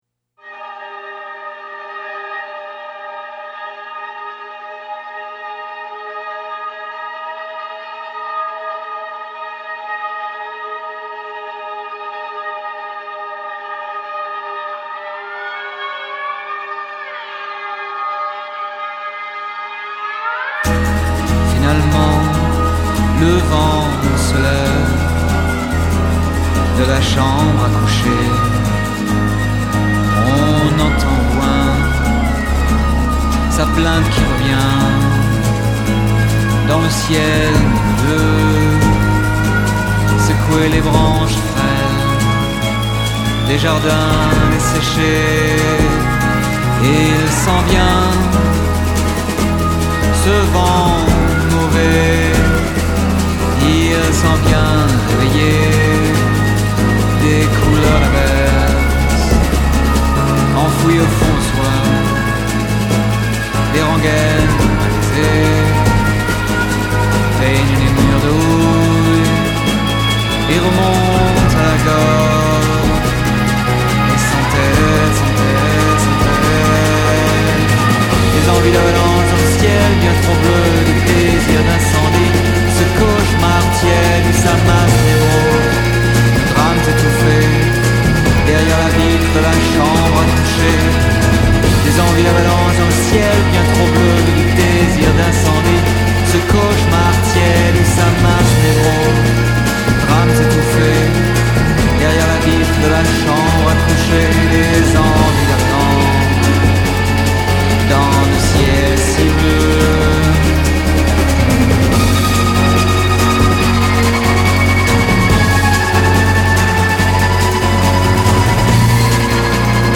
is brisky and quite powerful (chamber-speed pop?)